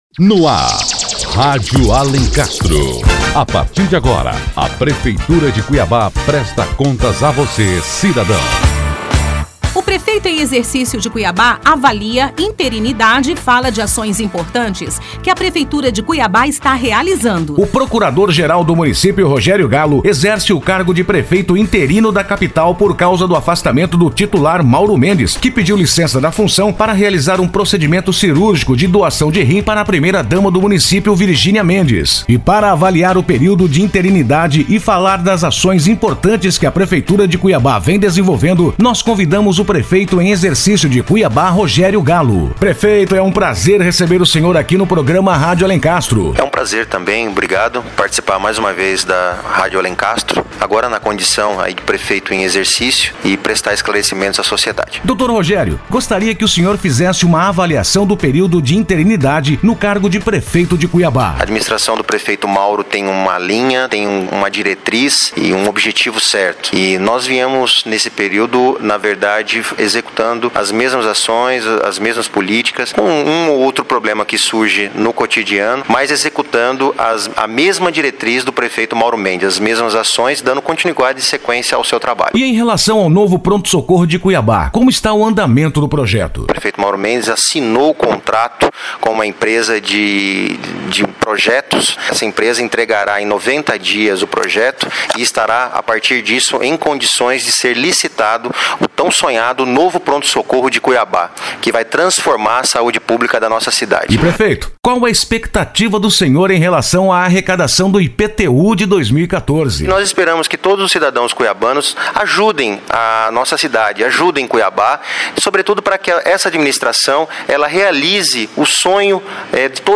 O Procurador Geral do Município, Rogério Gallo, fala sobre as principais ações da Prefeitura de Cuiabá enquanto...